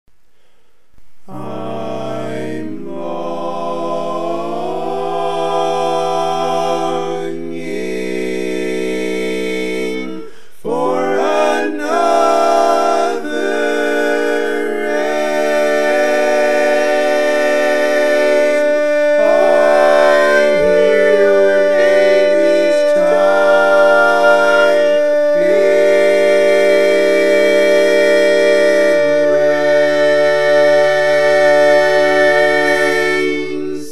Key written in: E♭ Major
How many parts: 4
Type: Barbershop
All Parts mix:
Learning tracks sung by